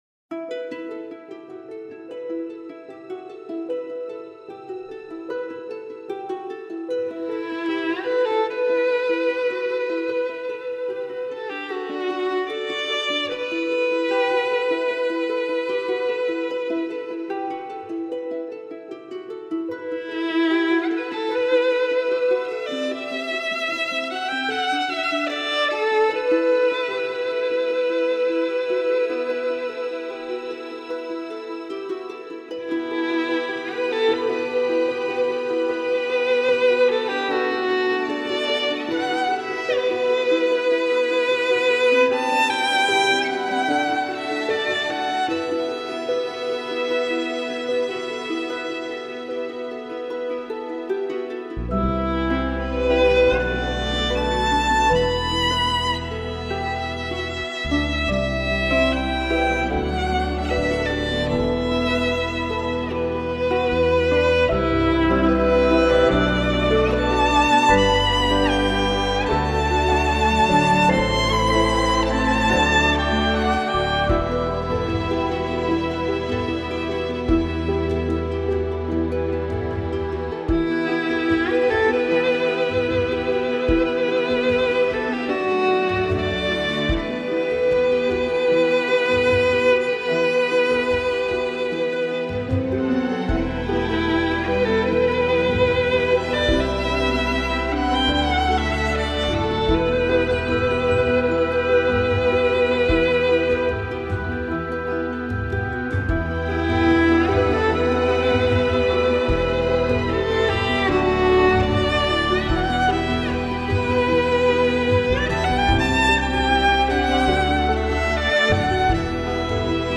آرامش بخش , پیانو , عصر جدید , موسیقی بی کلام , ویولن